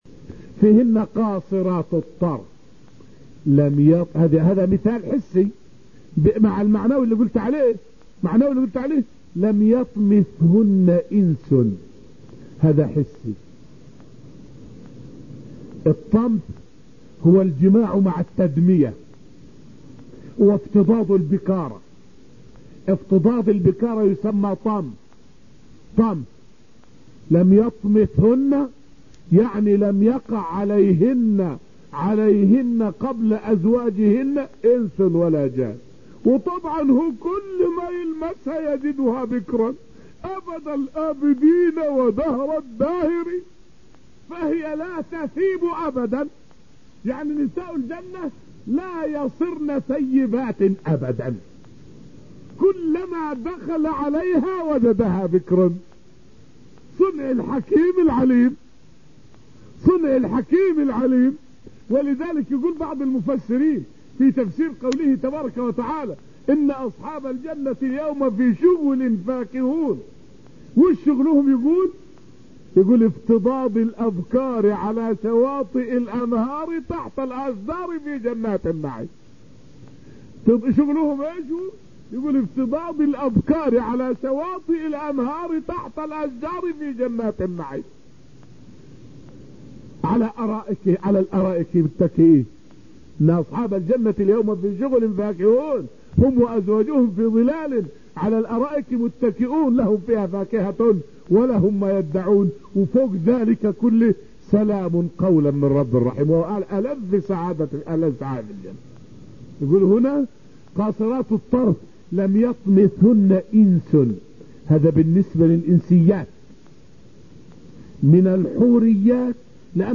فائدة من الدرس الثاني عشر من دروس تفسير سورة الرحمن والتي ألقيت في المسجد النبوي الشريف حول حوريات الجنة وأن كلهن أبكار، ولا يصرن ثيبات أبدًا.